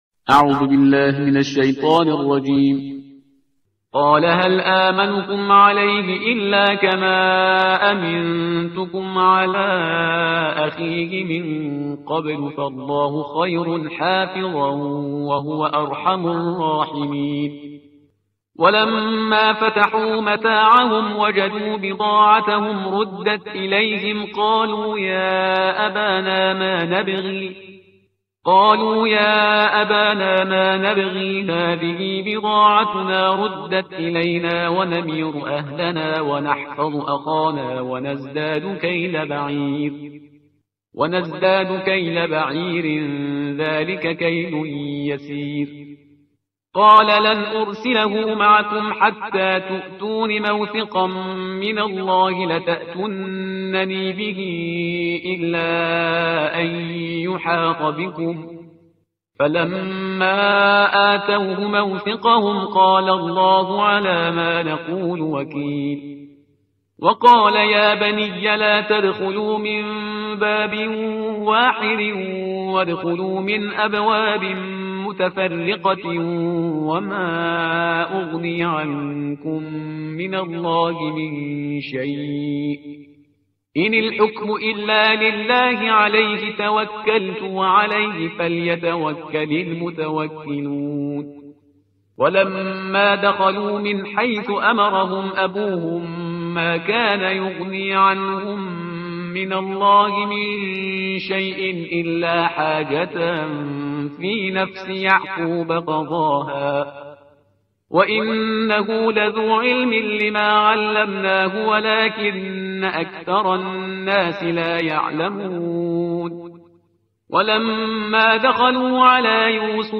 ترتیل صفحه 243 قرآن – جزء سیزدهم